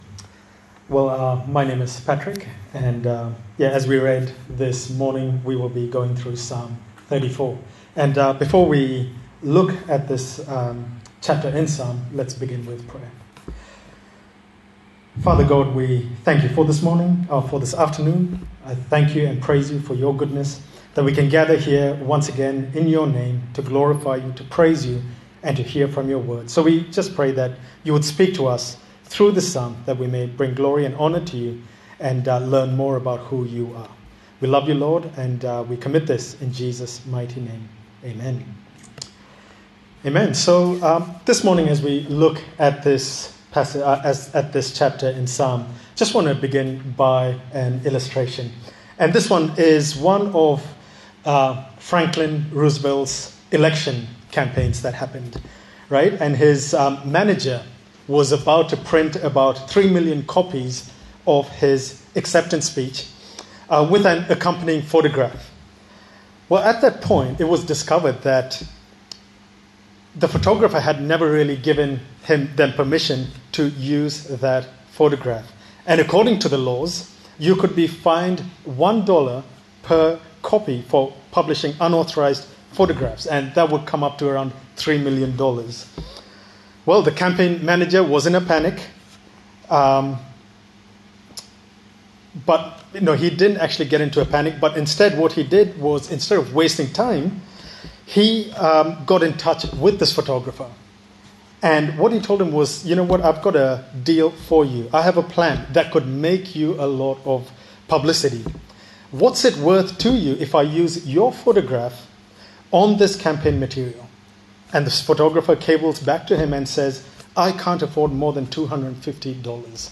A sermon in the series 'Songs for Summer' featuring the book of Psalms.
Psalm 34 Service Type: Sunday Service A sermon in the series 'Songs for Summer' featuring the book of Psalms.